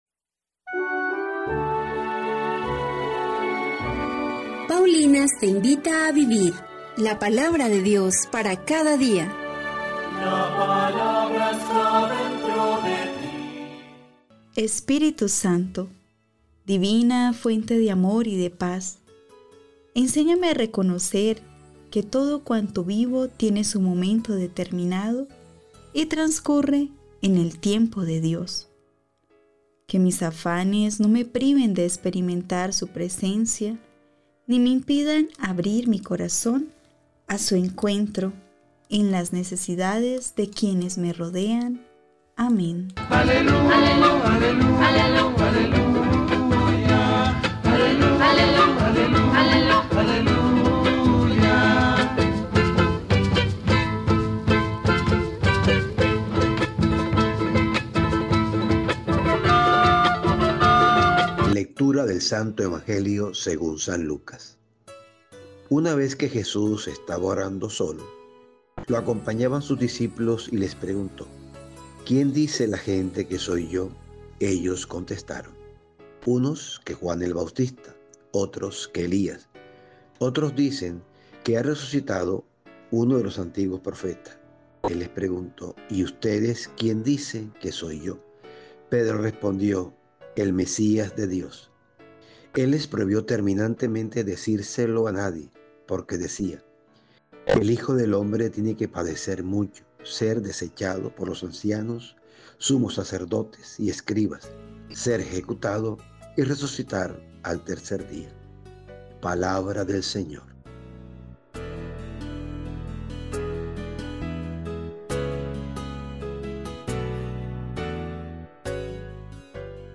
Liturgia-27-de-Septiembre.mp3